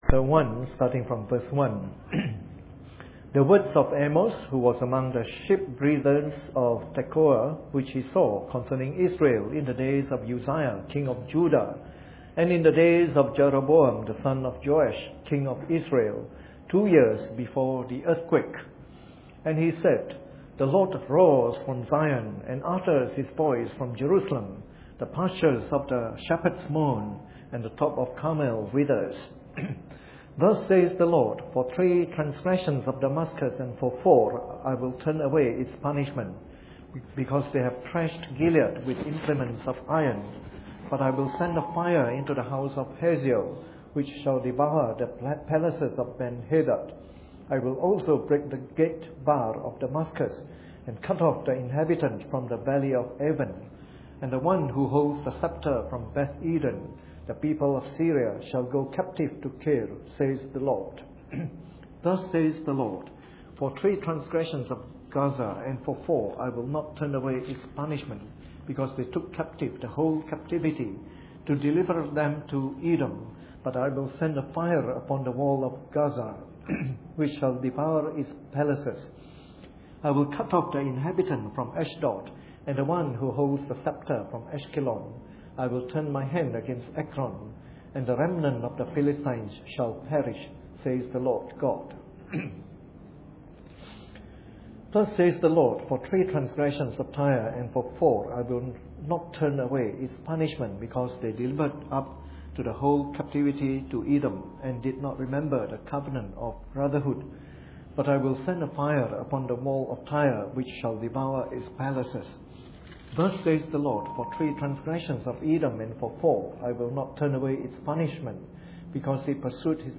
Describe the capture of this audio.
Preached on the 14th of November 2012 during the Bible Study, from our series on “The Minor Prophets.”